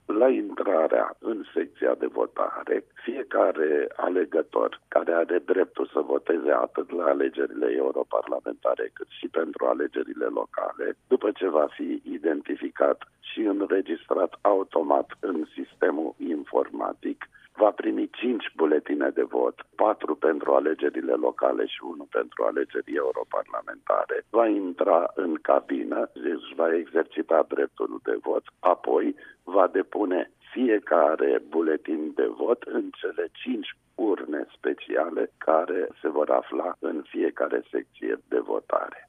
Campania electorală ia startul în 10 mai, pentru ambele scrutine şi până atunci este interzis, prin lege, să fie puse afişe cu numele competitorilor electorali şi funcţia pentru care candidează, a mai spus astăzi într-un interviu la RRA preşedintele Autorităţii Electeroale Permanente, Toni Greblă.